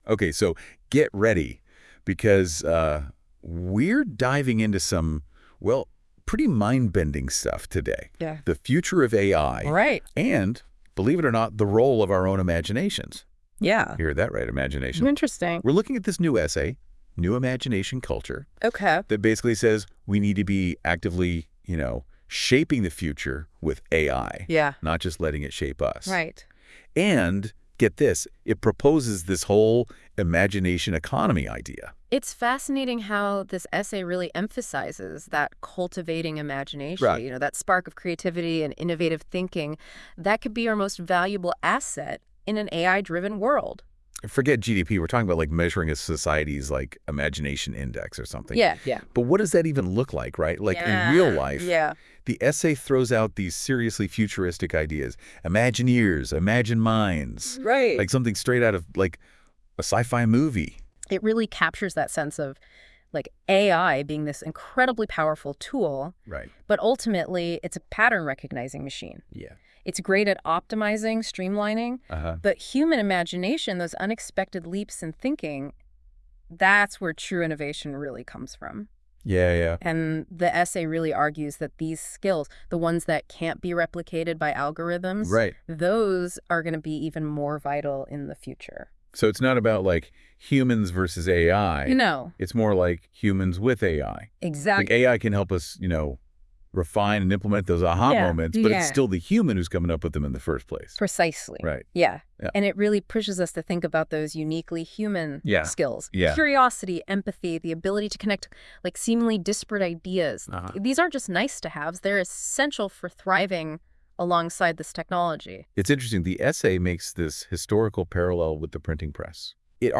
I uploaded it as a PDF file and asked NotebookLM to generate an audio summary. Building the Imagination Economy Summary Summary This audio summarizes an essay that argues for fostering a "culture of imagination" to counter the potential negative effects of artificial intelligence (AI) on human creativity and consciousness. It proposes a future where "imagineers" work in "imagineMines" — centers dedicated to researching and enhancing imagination through collaboration.